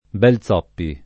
[ b H l Z0 ppi ]